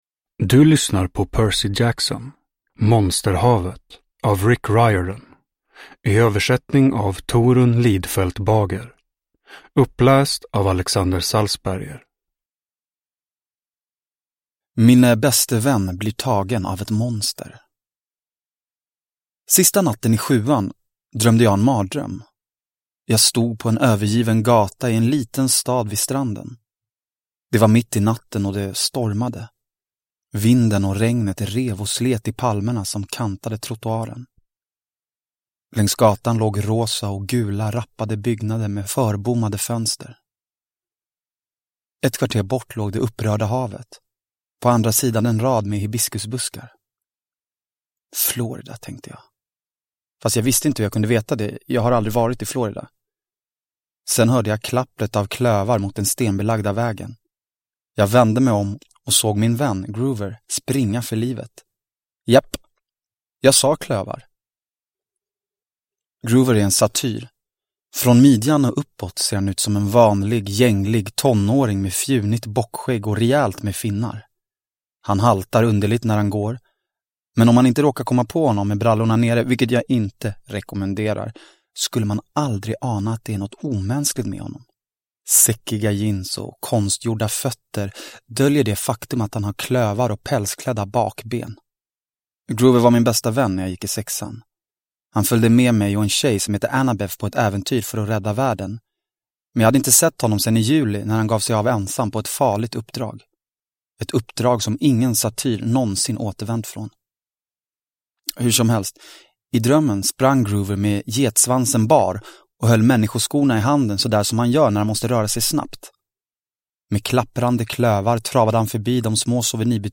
Monsterhavet – Ljudbok – Laddas ner